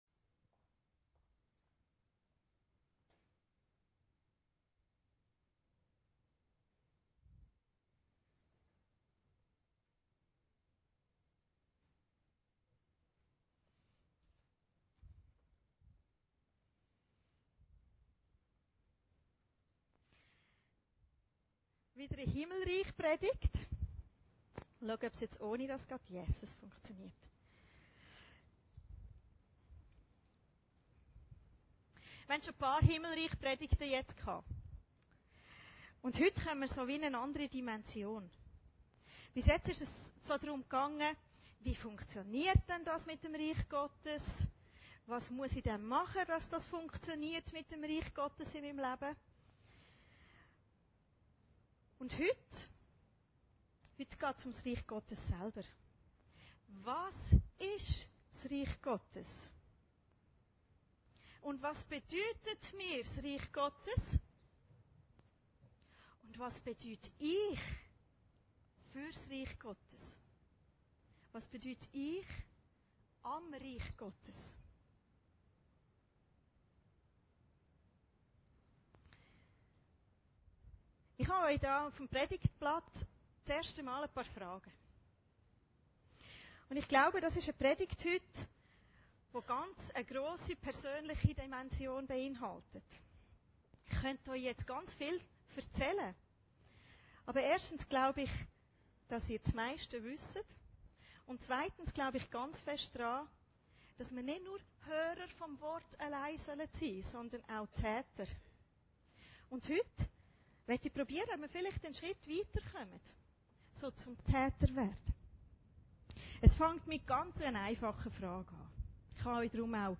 Predigten Heilsarmee Aargau Süd – Schatz und Perle